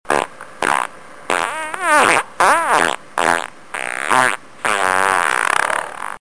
SFX实用未整理持续的放屁声音效下载
SFX音效